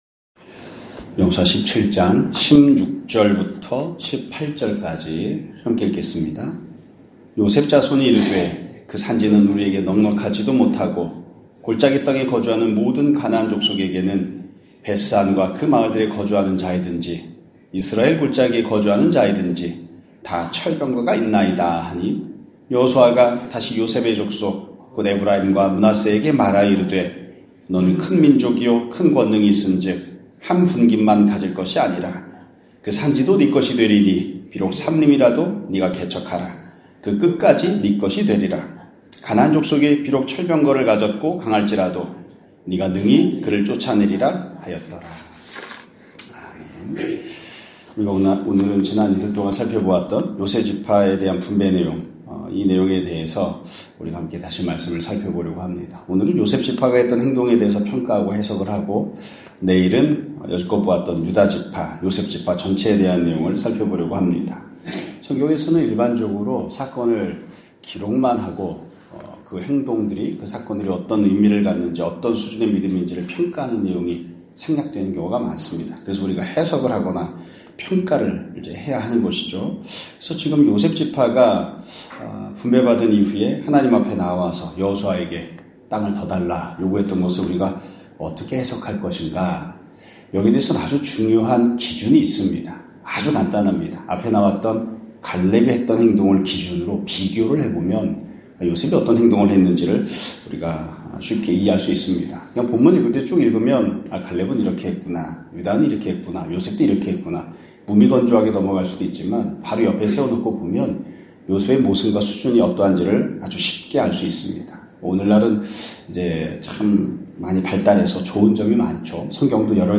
2024년 12월 19일(목요일) <아침예배> 설교입니다.